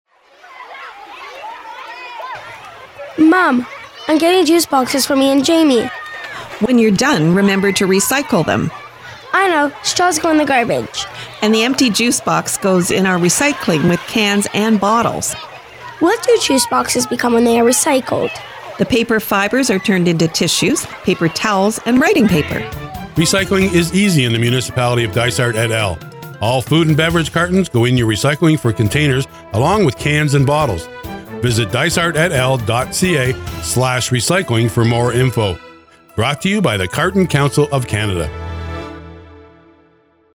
Dysart-et-al-Radio-Ad-1-Child-and-Parent.mp3